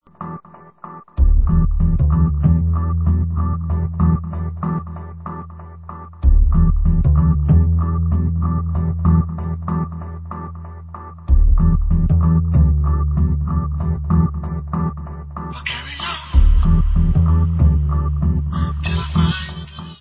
sledovat novinky v oddělení Dance/House